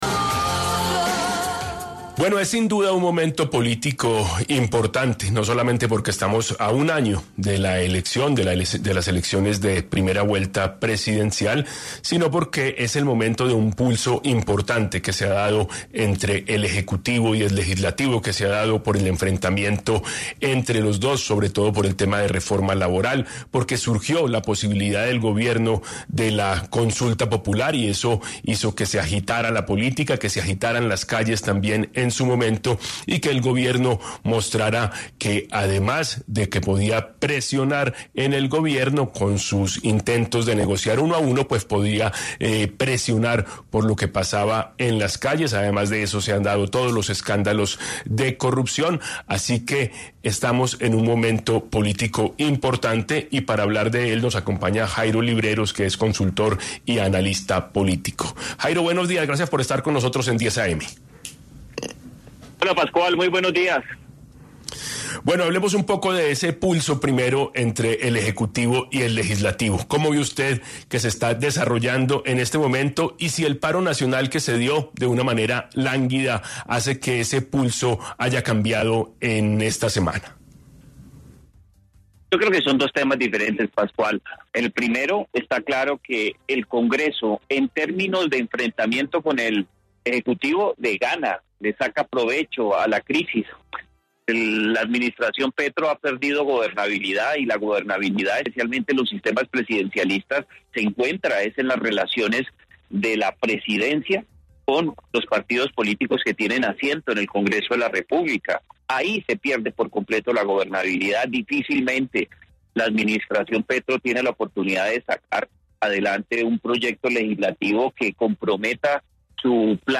En entrevista con 10AM Hoy por Hoy de Caracol Radio